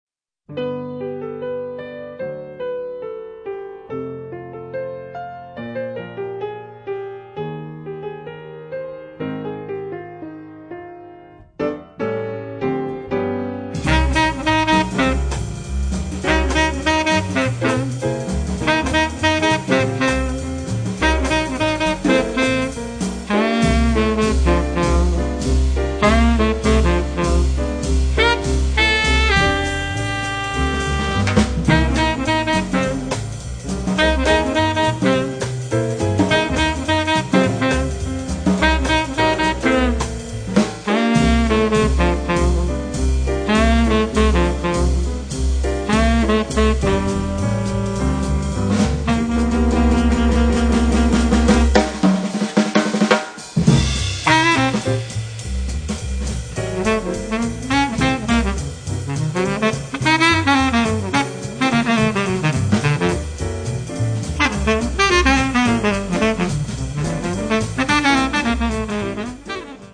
sax baritono, flauto
piano
double bass
batteria